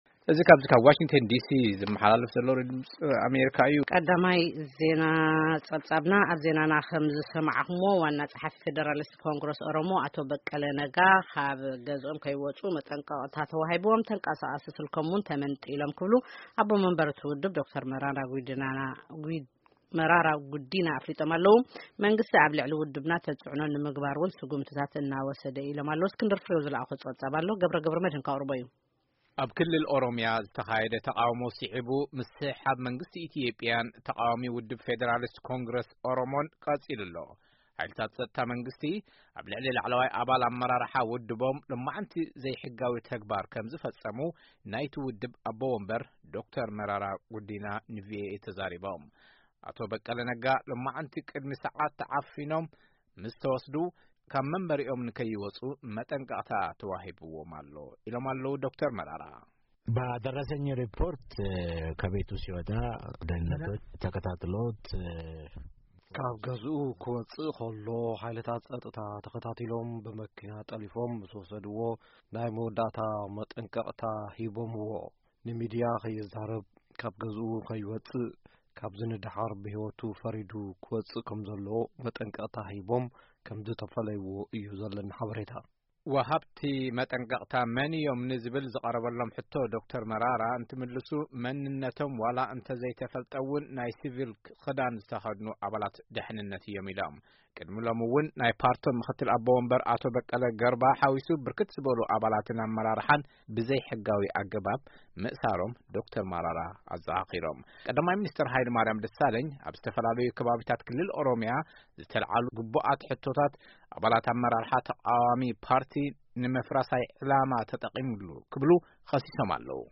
ቃለ-መጠይቕ ምስ ኣቦ መንበር ፈደራሊስት ኮንግረስ ኦሮሞ ዶ/ር መረራ ጉዲና